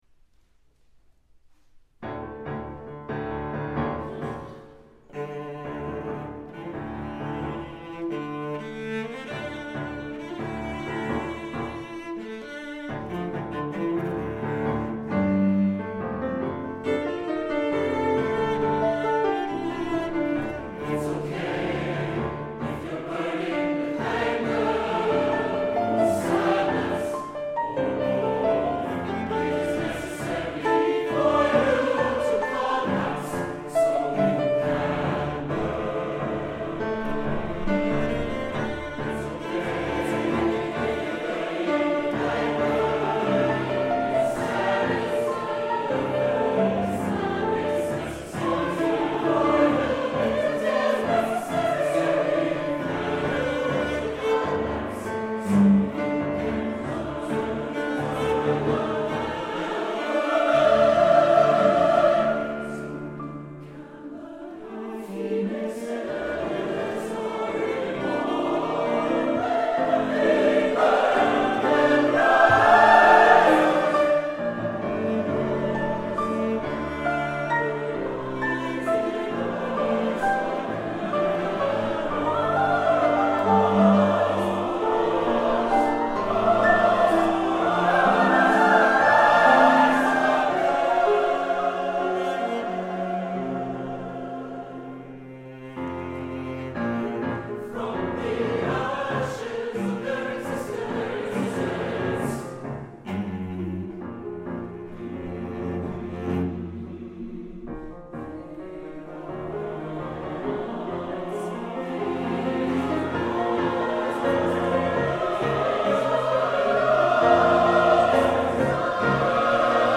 SATB div. choir, cello, piano